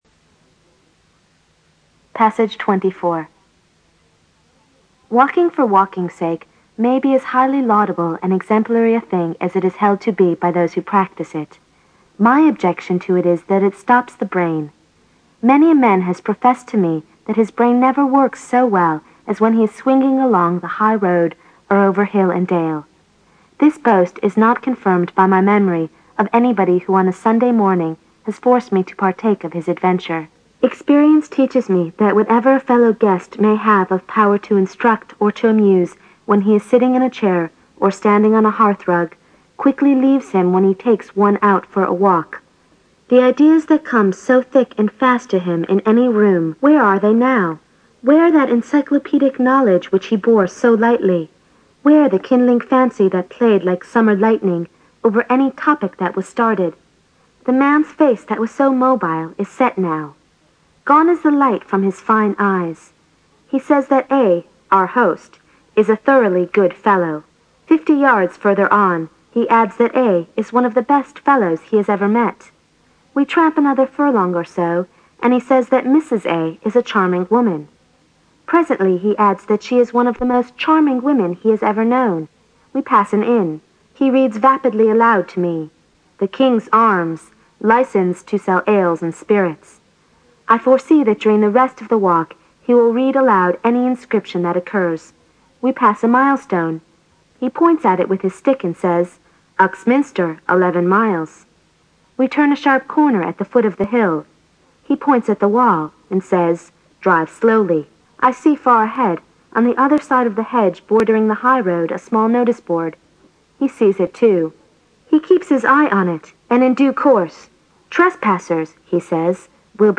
新概念英语85年上外美音版第四册 第24课 听力文件下载—在线英语听力室